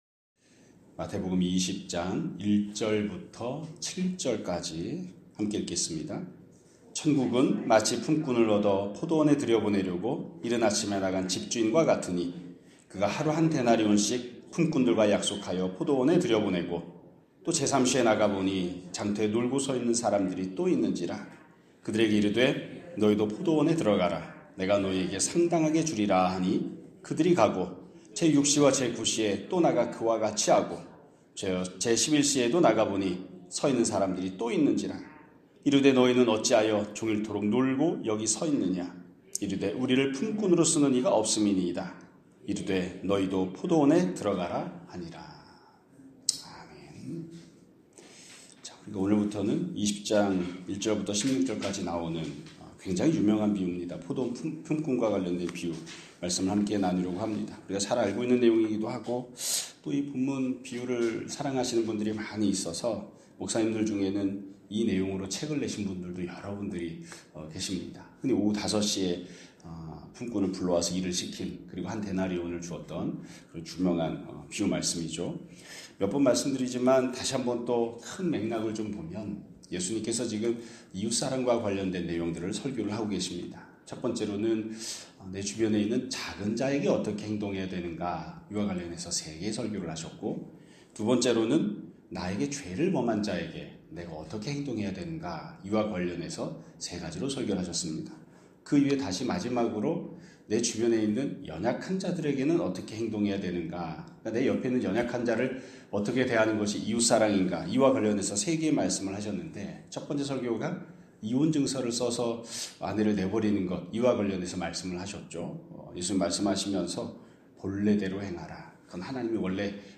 2026년 1월 13일 (화요일) <아침예배> 설교입니다.